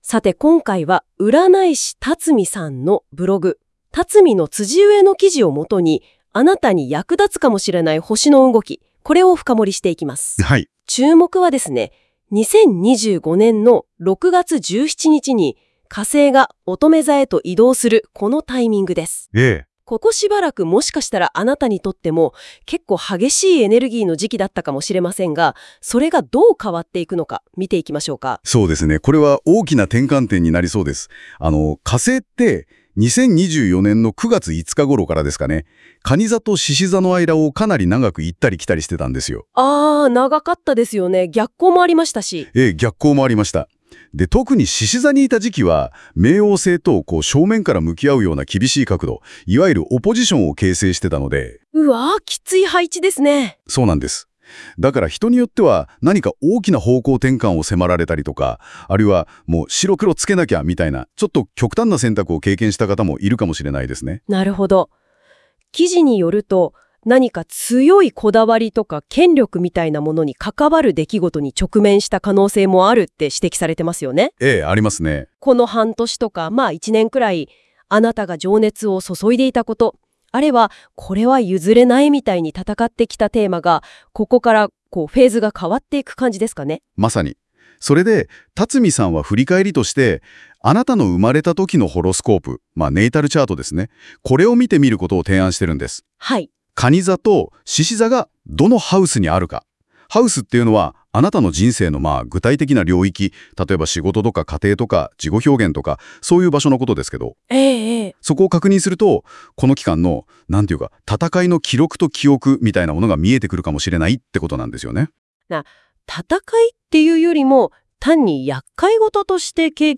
今回、実験的にnotebookLMで音声概要をしてみました。